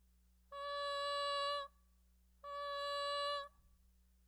7) Simulating bone-transmission sound
I think it places somewhere between air and bone-conducted sounds.